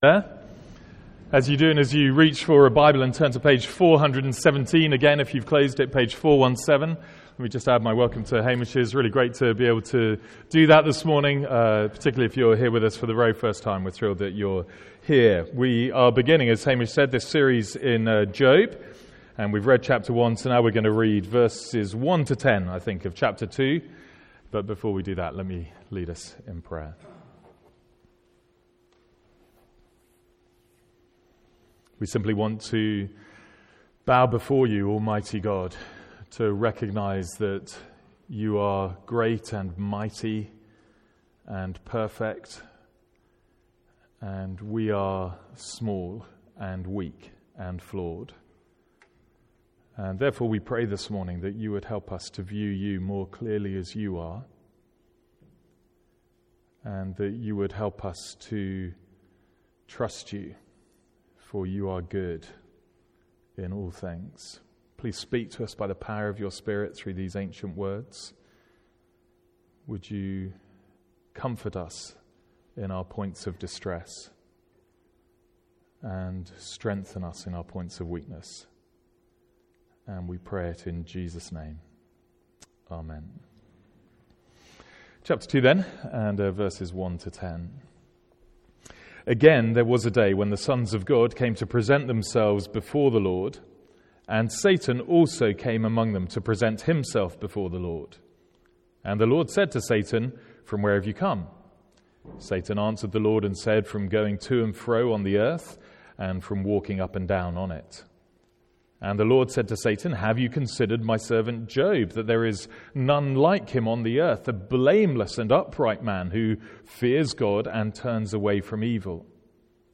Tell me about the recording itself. Note: Second half of sermon was re-recorded after the initial event.